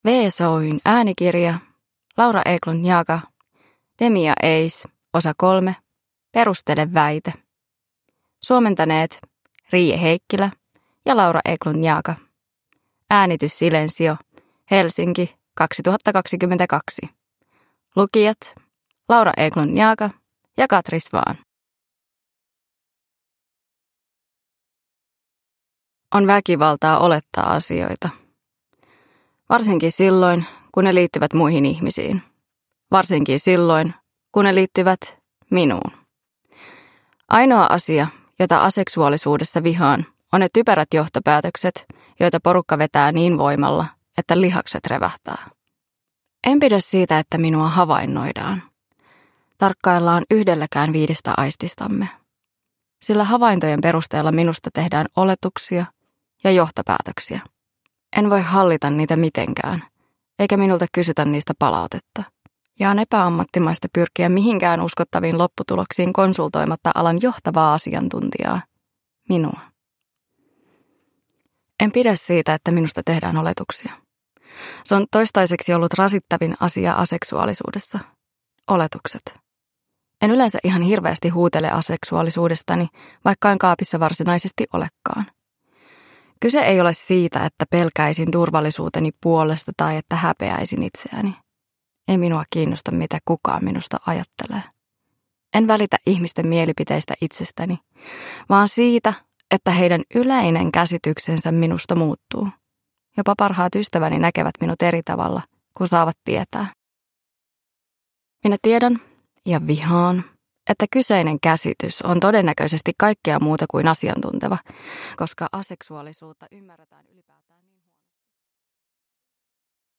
Demi & Ace 3: Perustele väite – Ljudbok – Laddas ner